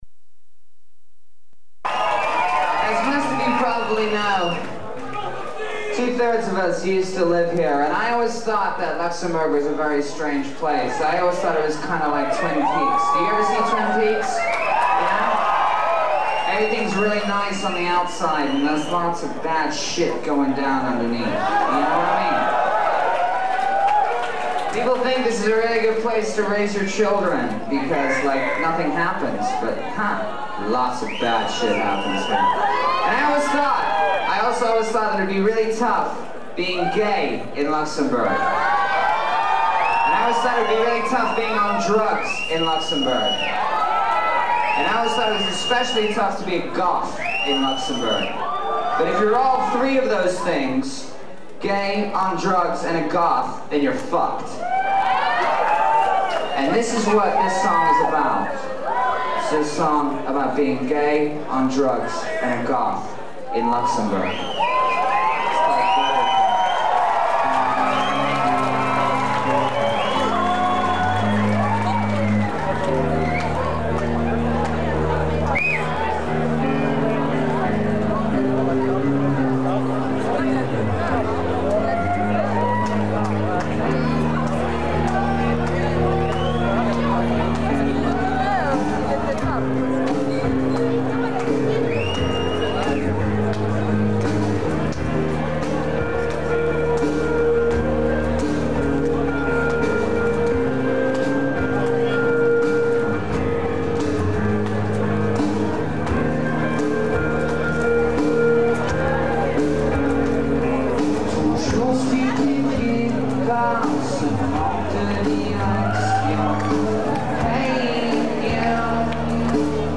francais live
extrait de luxembourg 99 (2eme date)